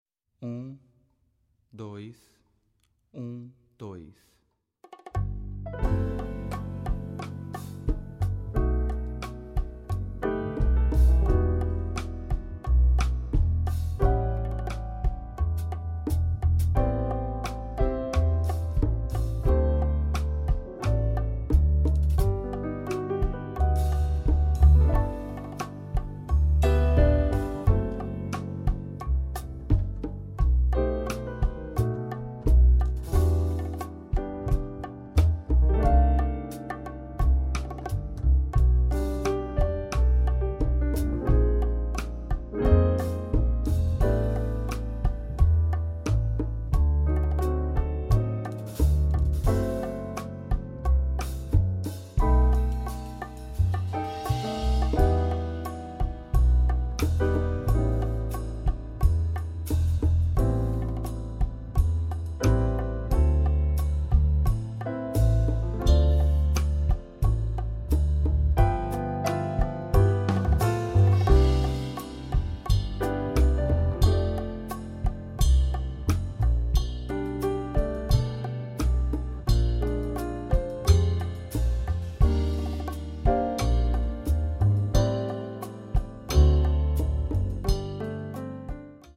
Voicing: Alto and Bari Sax w/ Audio